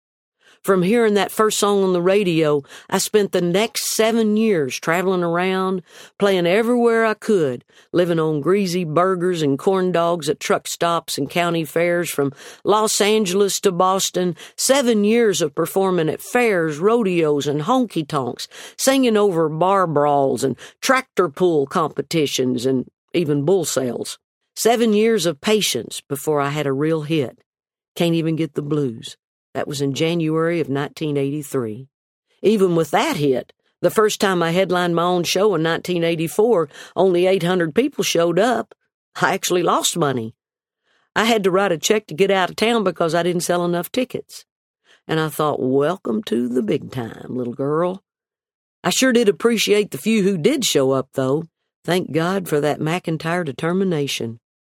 Audio / From the audio version of her new book, Not That Fancy, Reba McEntire talks about the beginning of her music career.